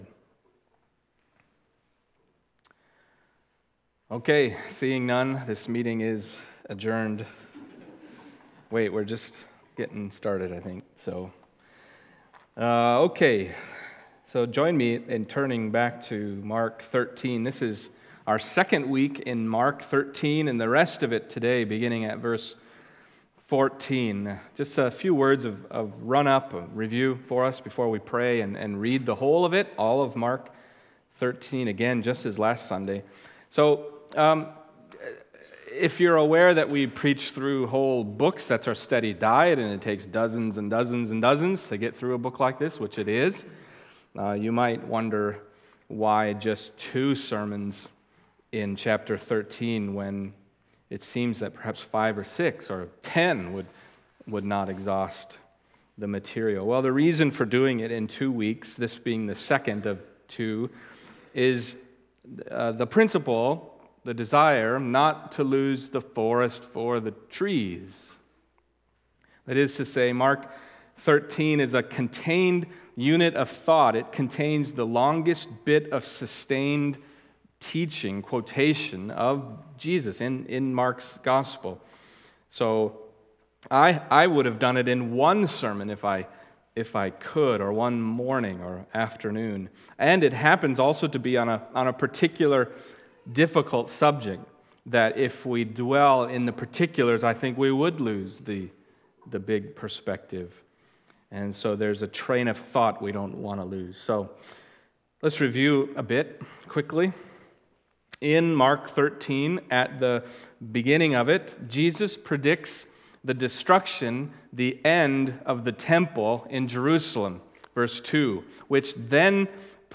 Passage: Mark 13:14-37 Service Type: Sunday Morning